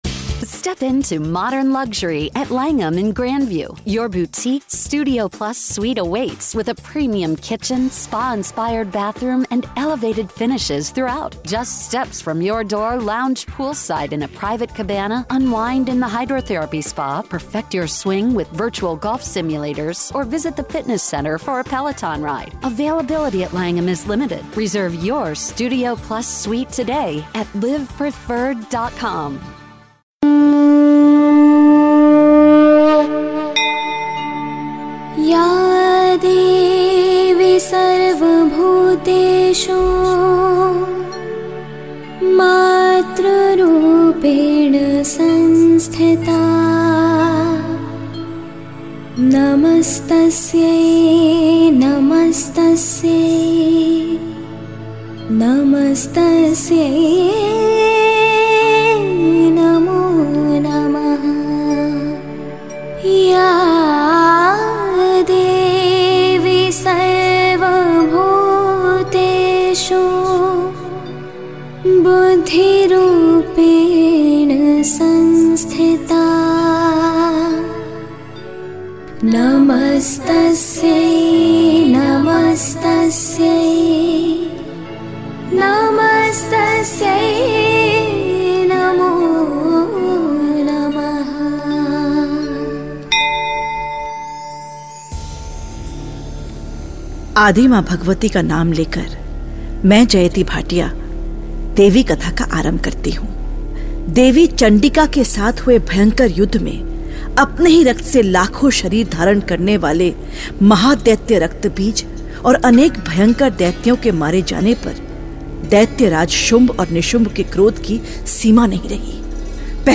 The Ancient Story Goddess Durga narrated